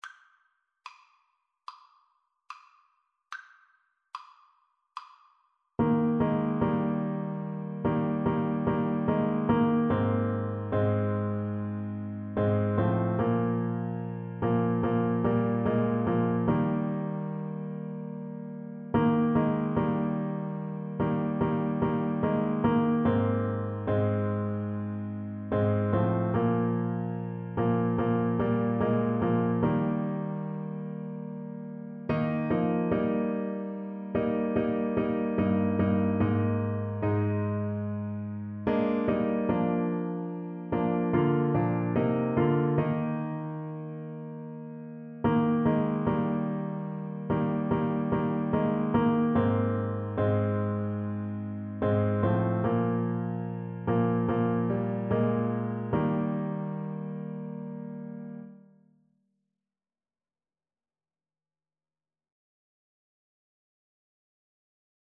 Alto Saxophone
4/4 (View more 4/4 Music)
Classical (View more Classical Saxophone Music)